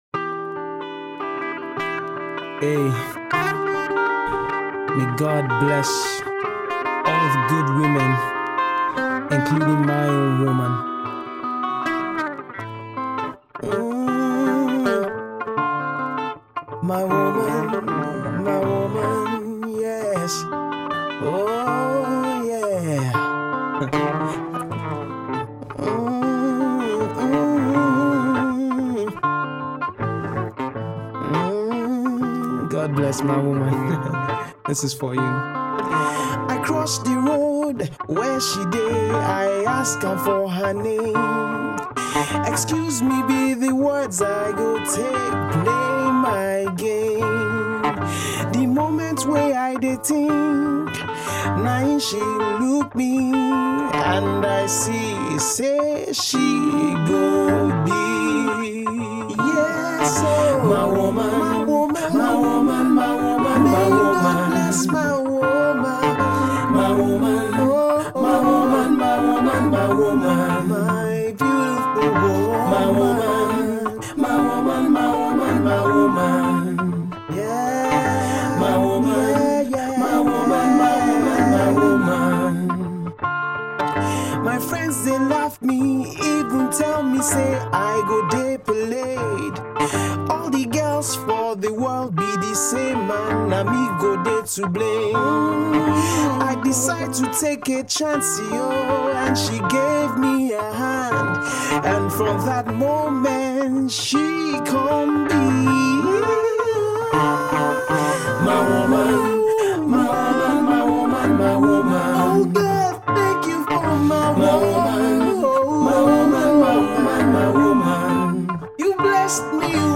Folk-tinged Africana Pop